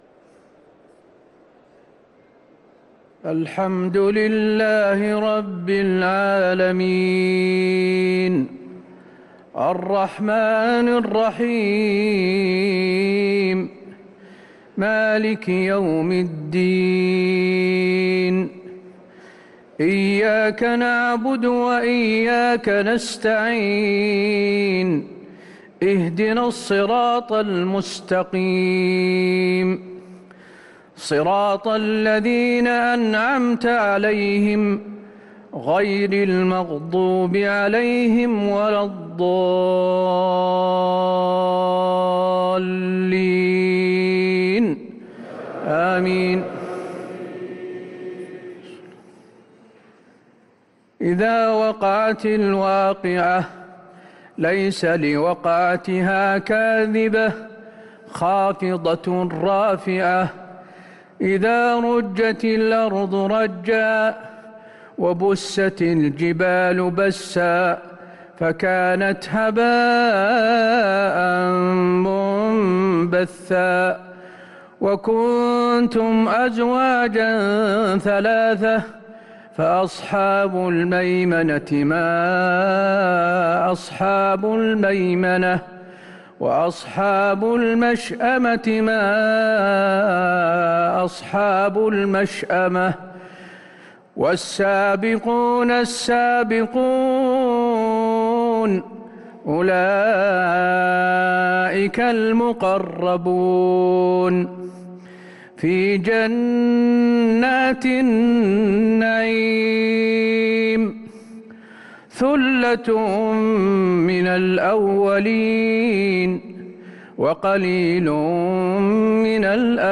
صلاة العشاء للقارئ حسين آل الشيخ 24 جمادي الأول 1445 هـ
تِلَاوَات الْحَرَمَيْن .